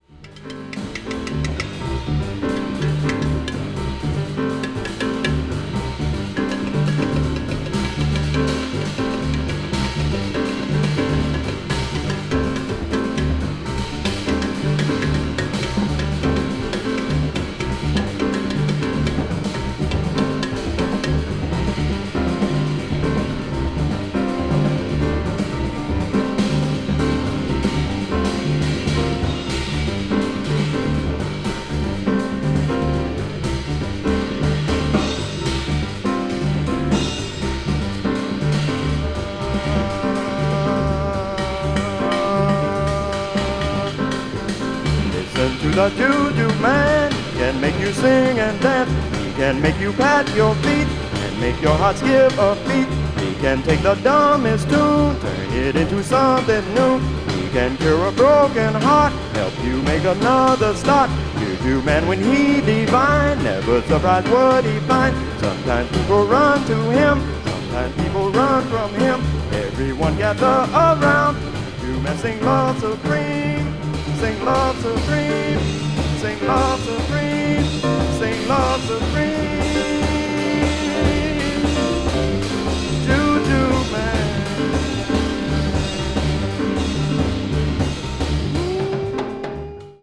saxophonist
recorded live at the Montreux Jazz Festival in Switzerland